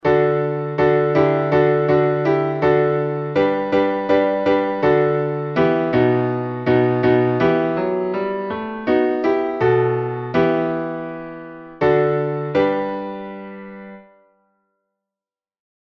Малая ектения